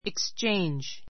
ikstʃéindʒ